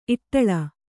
♪ iṭṭaḷa